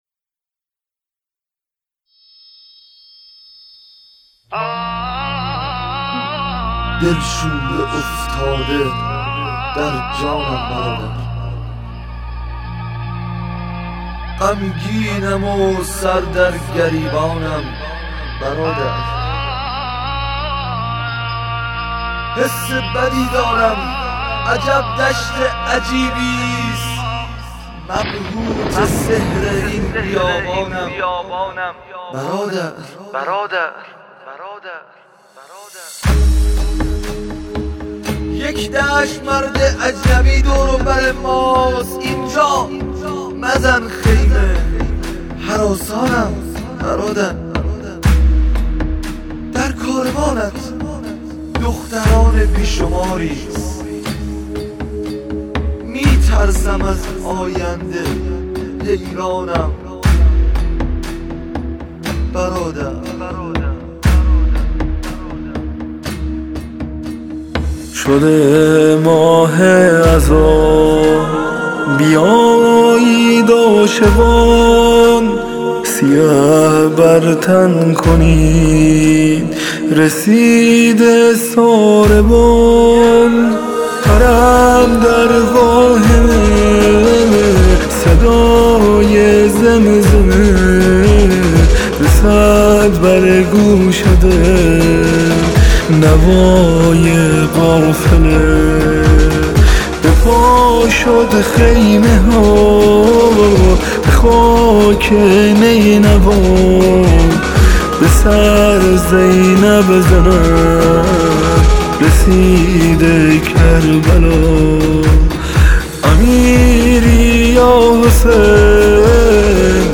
آهنگ جدید و غمگین ۹۸
ویژه محرم